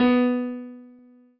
piano-ff-39.wav